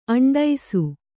♪ aṇḍaisu